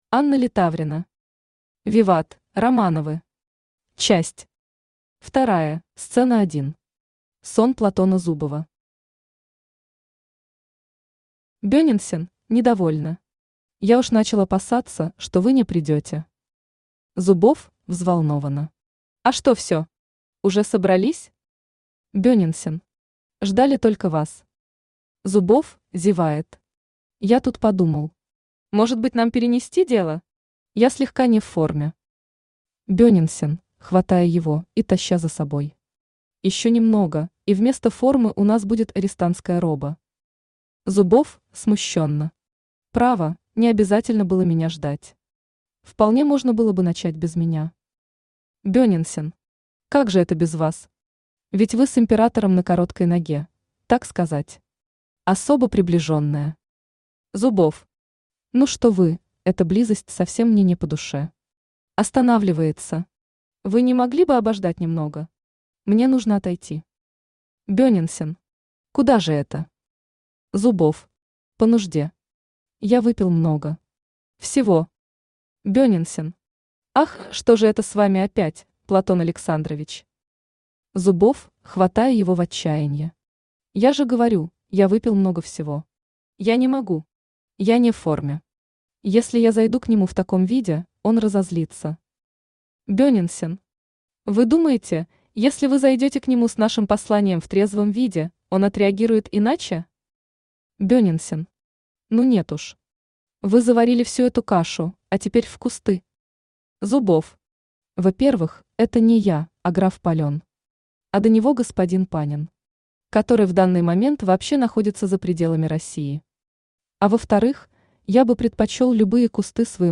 Аудиокнига Виват, Романовы! Часть II | Библиотека аудиокниг
Часть II Автор Анна Литаврина Читает аудиокнигу Авточтец ЛитРес.